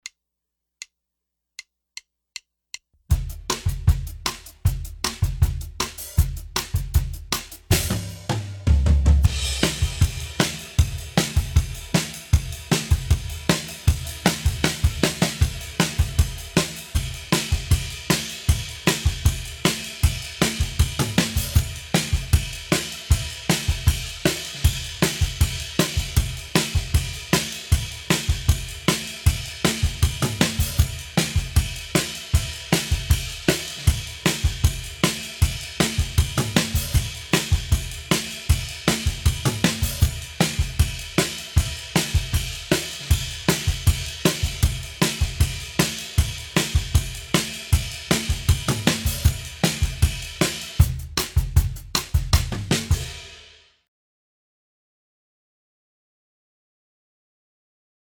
Bum Bum peng DAS ist jedenfalls keine Library :D. Es hat sehr viel Dynamik und man hört irgendwie leicht die reinstreuenden Becken durch nicht schnell genug geschlossener Gates in andere Mikrofone.
Aber trotzdem sauber gespielt.